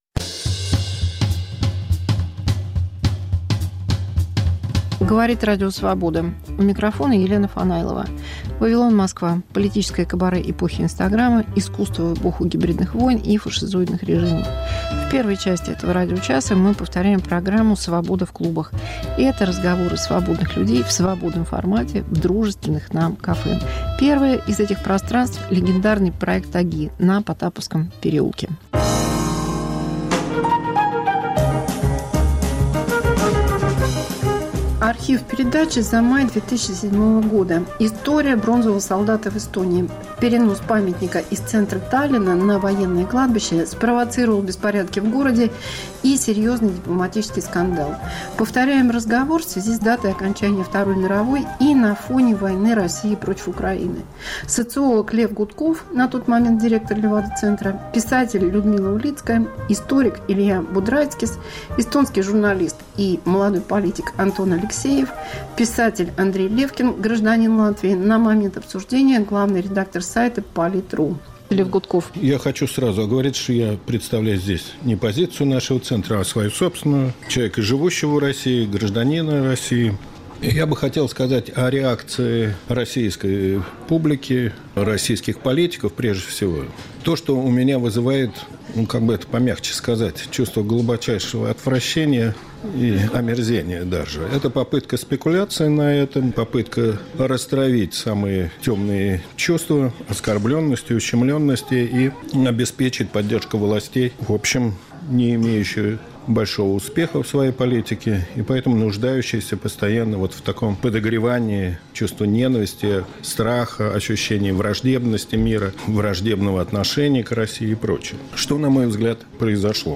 Мегаполис Москва как Радио Вавилон: современный звук, неожиданные сюжеты, разные голоса. 1. Архив 2007 о переносе памятника советскому солдату в Таллинне. 2. Антивоенный вечер в Мемориале, часть 5